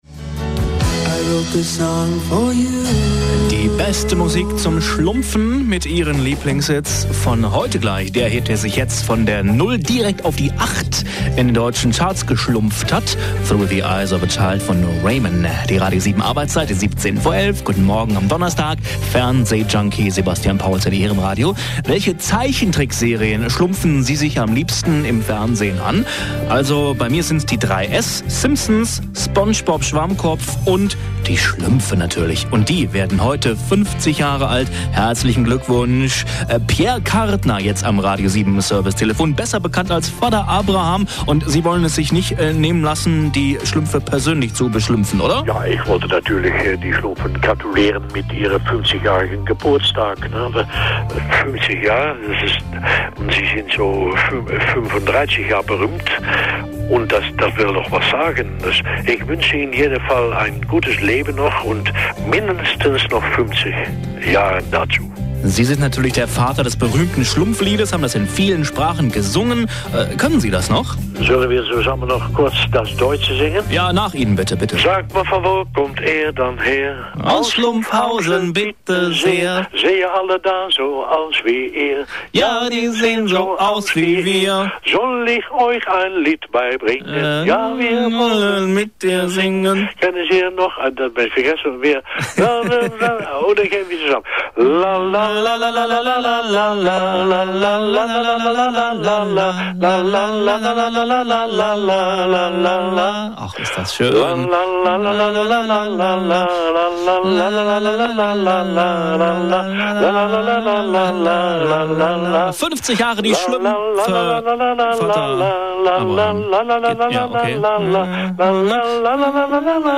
in meiner Sendung standesgemäß gratuliert.